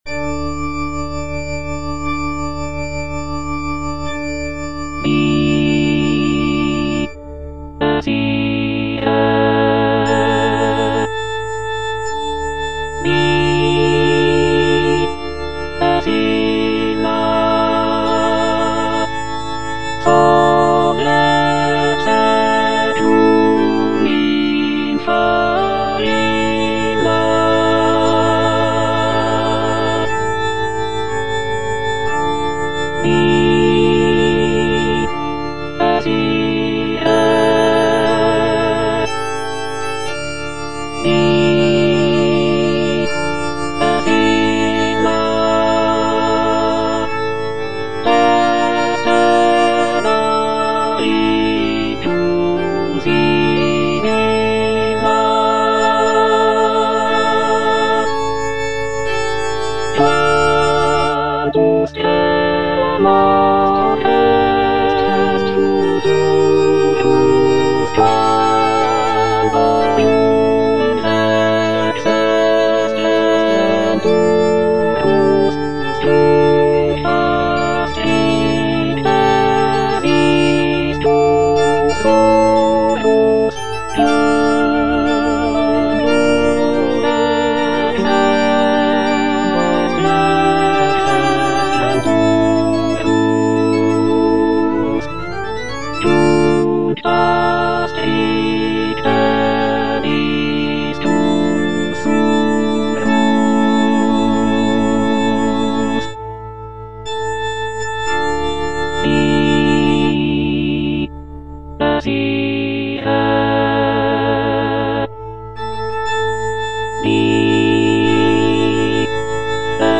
Alto (Emphasised voice and other voices) Ads stop
is a sacred choral work rooted in his Christian faith.